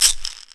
Perc Uzi.wav